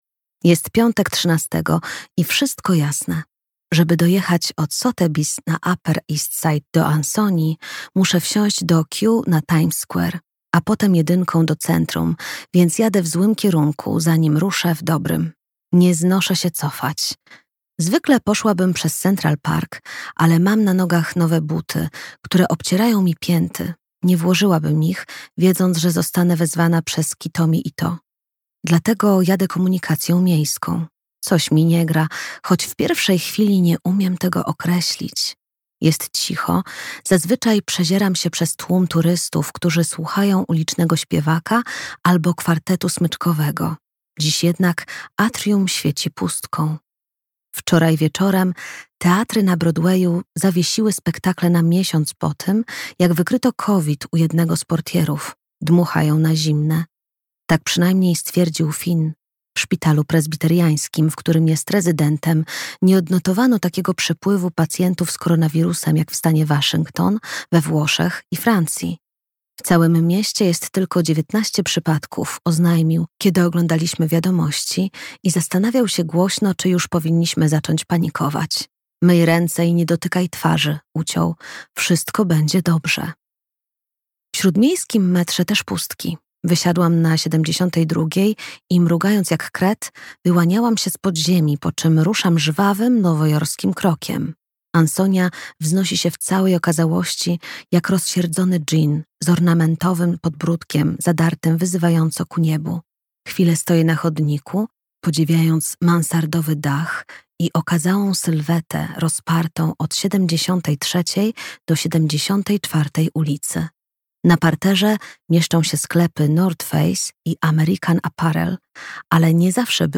Szkoda, że cię tu nie ma - Jodi Picoult - audiobook + książka